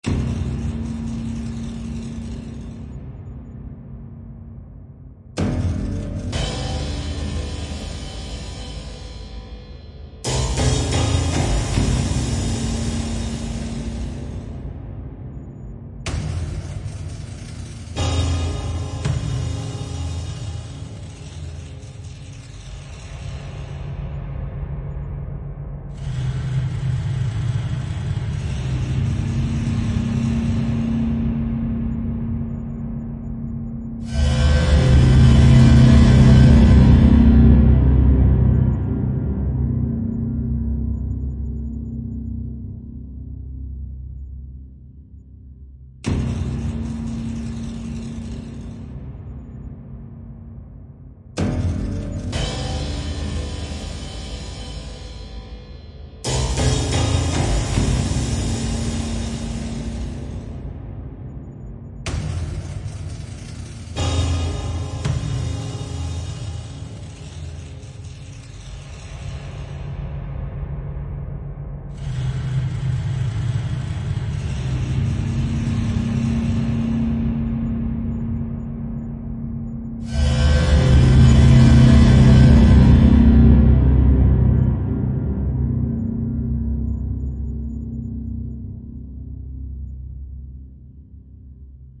描述：为优秀的益智游戏录制和处理的语音
标签： 卡通 语音 词组 准备好 游戏 获取
声道立体声